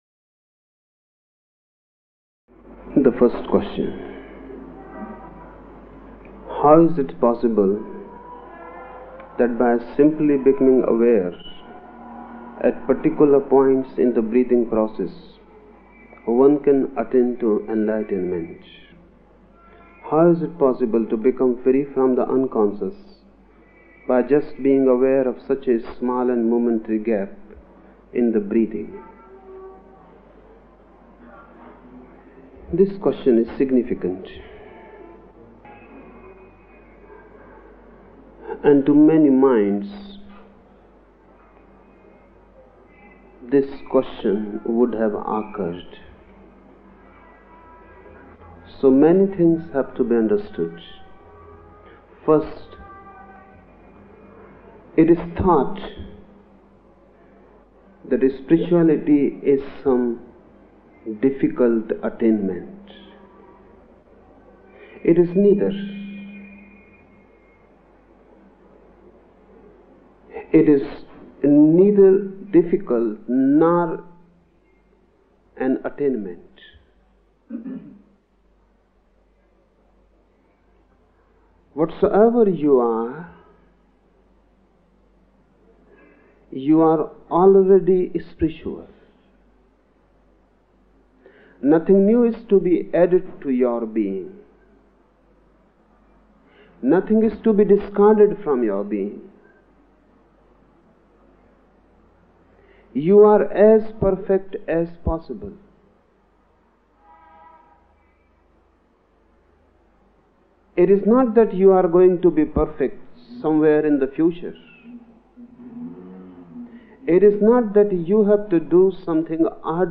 Each program has two parts, Listening Meditation (Osho discourse) and Satsang Meditation.
The Osho discourses in the listening meditations in this module are from the discourse series, The Book of Secrets in which Osho was speaking on Shiva’s 112 meditation techniques, and were given in Mumbai, India from 1972 to 1973.